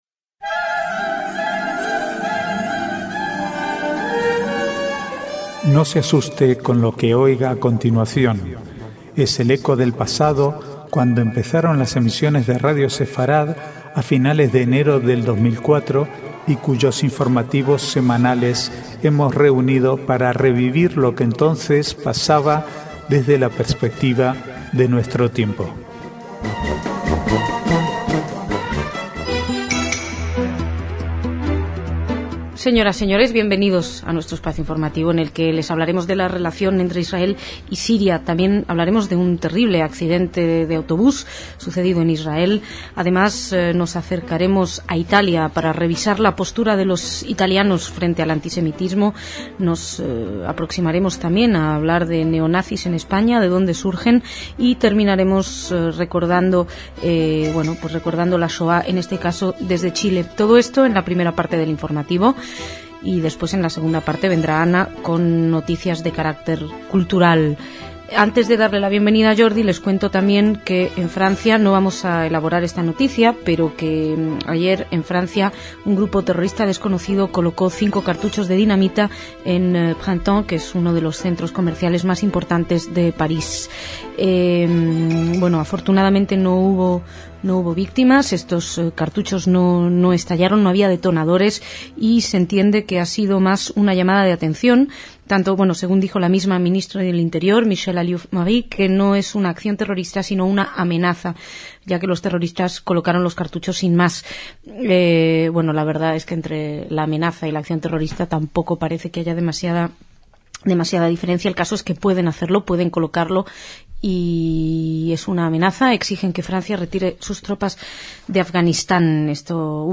Archivo de noticias del 17 al 19/12/2008